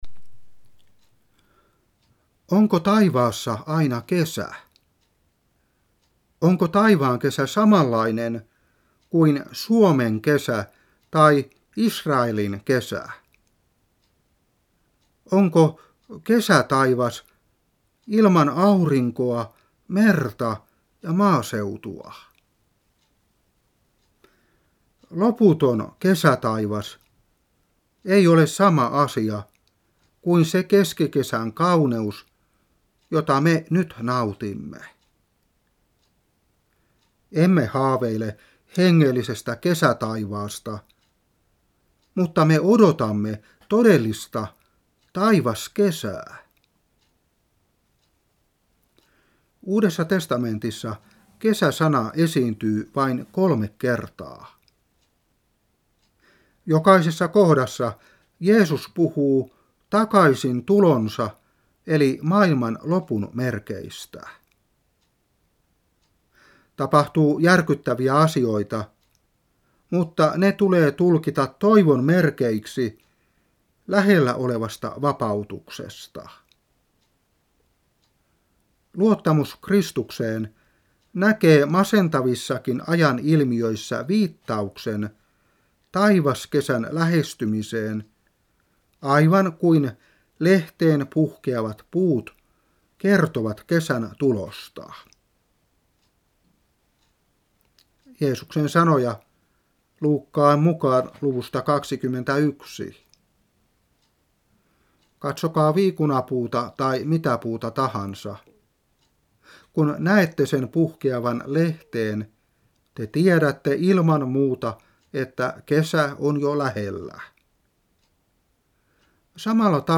Opetuspuhe 2013-6.